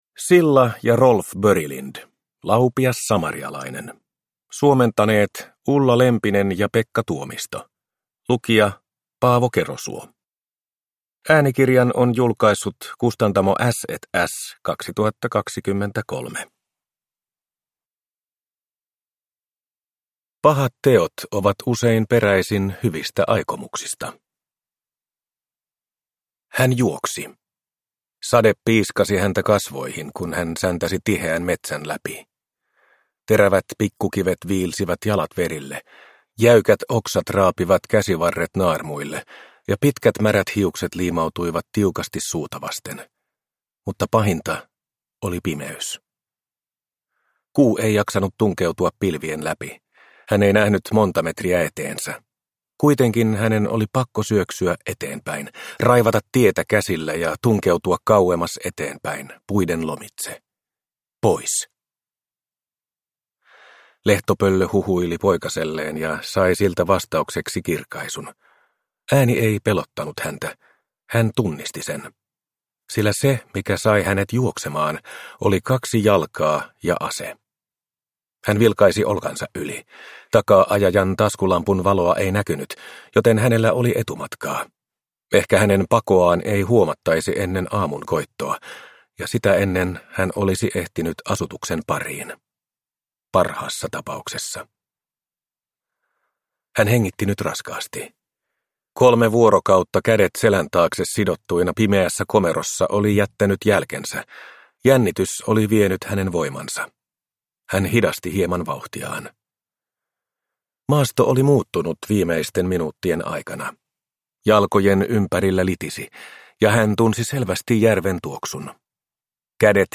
Laupias samarialainen – Ljudbok – Laddas ner